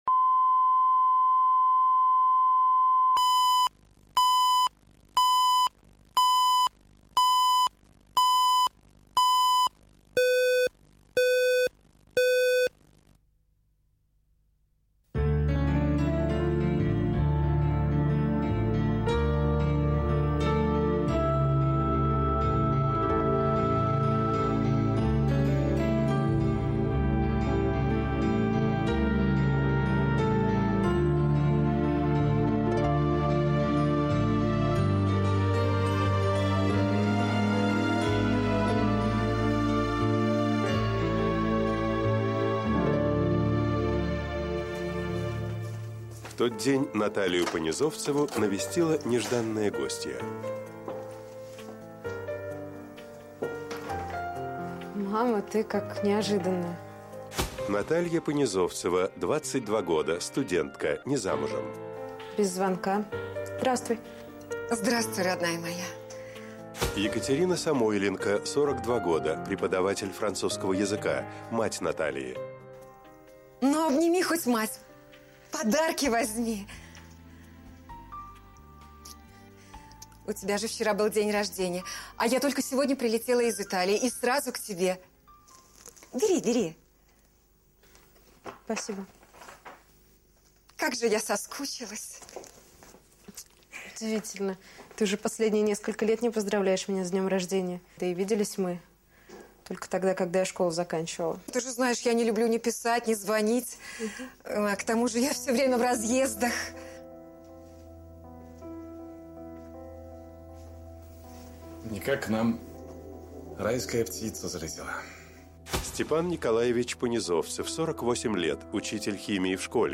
Аудиокнига Мамочка, не уходи | Библиотека аудиокниг